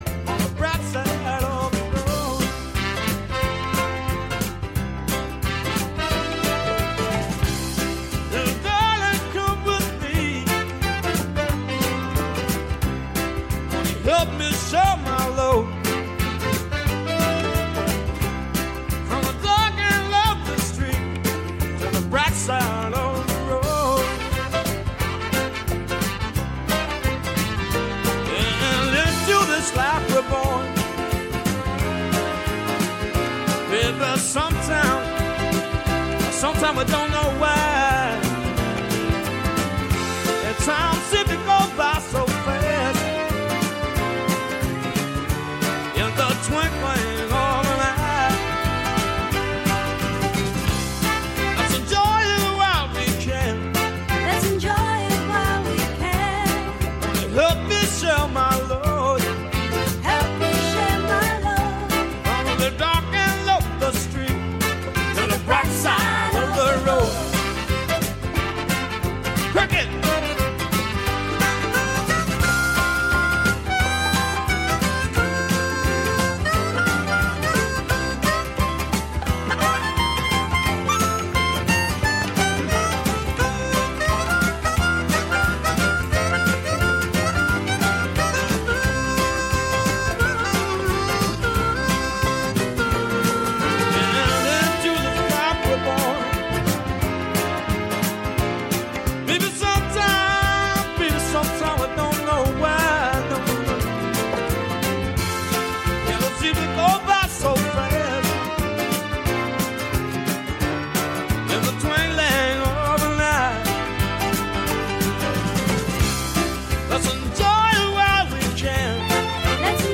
This week’s poetry reading is Truth, by G, courtesy of Librivox and Project Guetenberg.